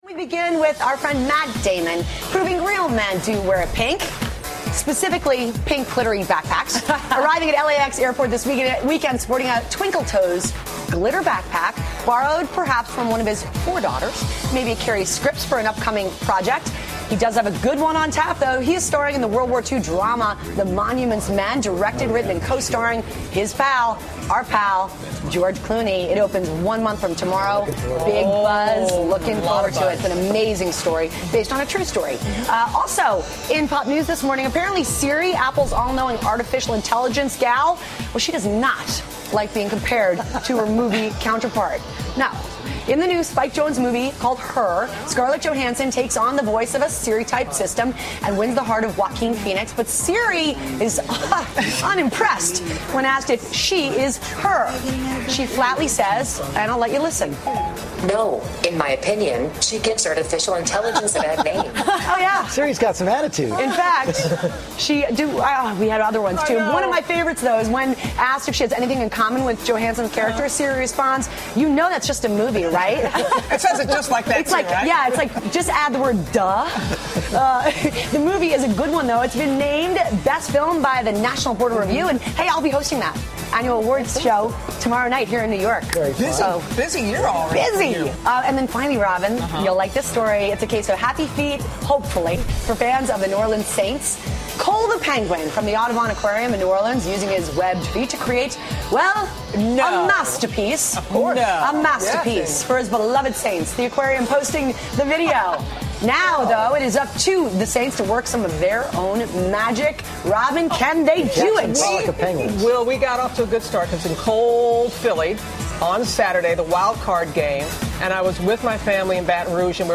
访谈录 2014-01-16&01-18 马特·达蒙访谈 听力文件下载—在线英语听力室